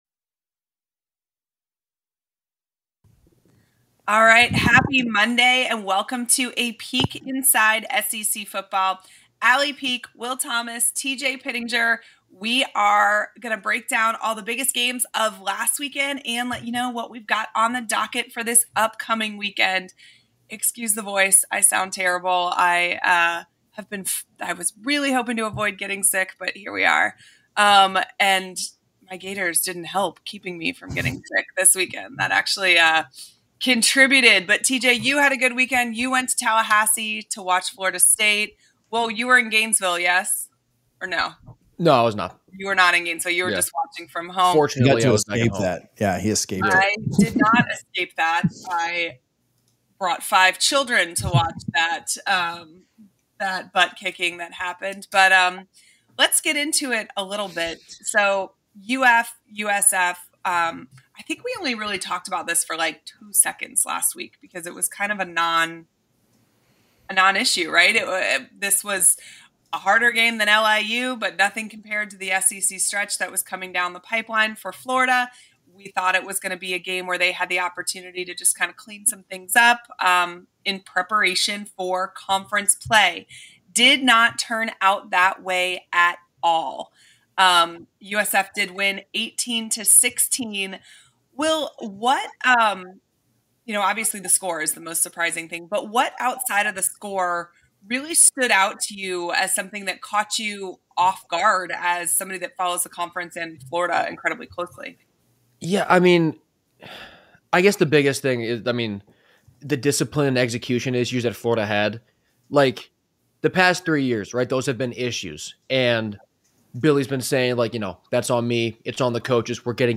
chat all things SEC Football. Today they's tralking about the biggest games from the weekend and the games to come!